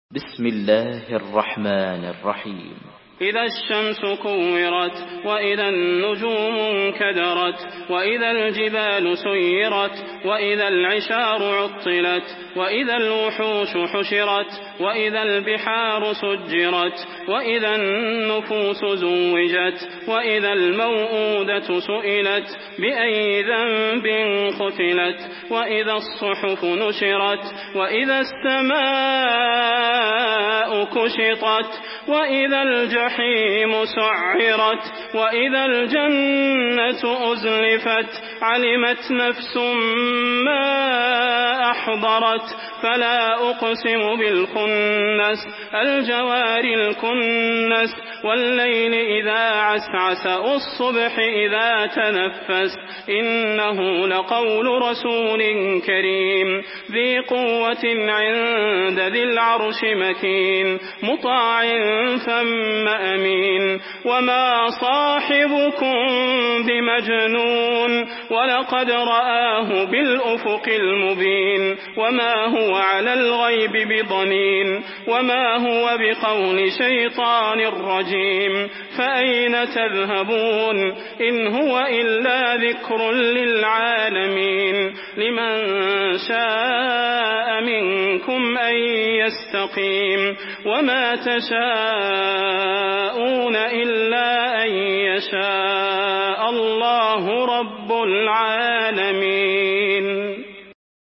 Surah At-Takwir MP3 by Salah Al Budair in Hafs An Asim narration.
Murattal